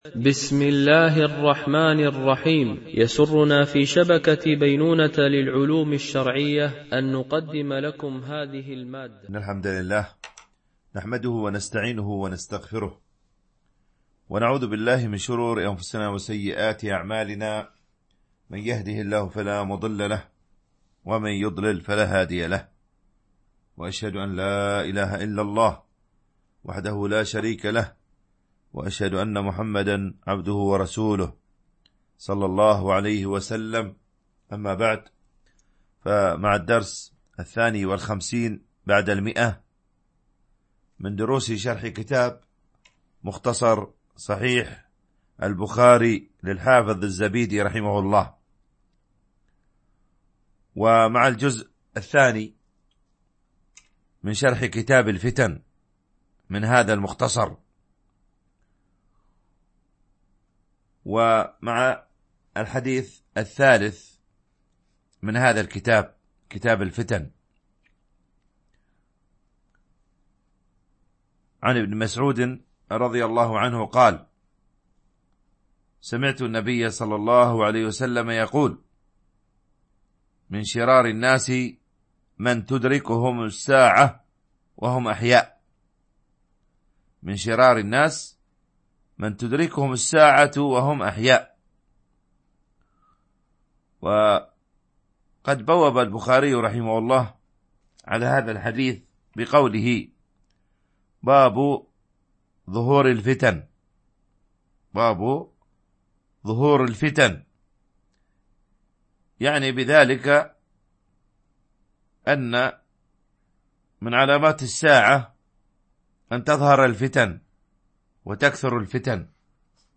شرح مختصر صحيح البخاري ـ الدرس 152 ( كتاب الفتن ـ الجزء الثاني )